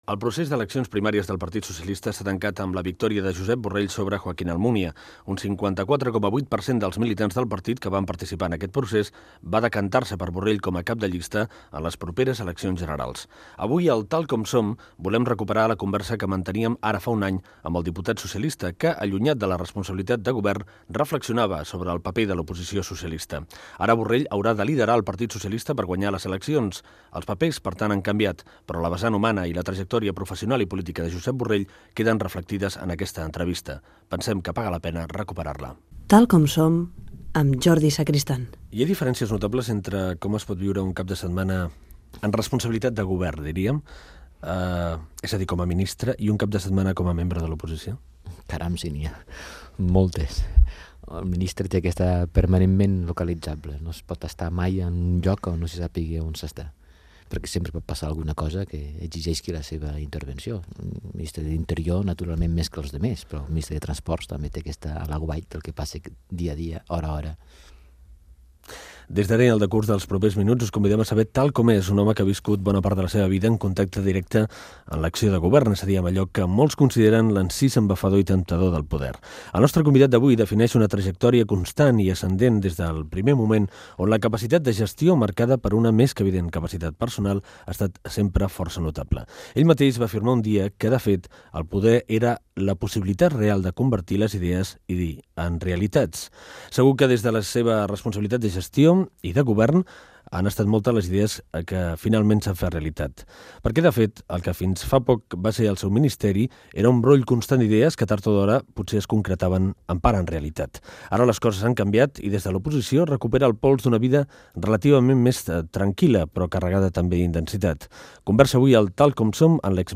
Fragment d'una entrevista al polític Josep Borrell, ex ministre d'Obres Públiques, Transport i Medi Ambient.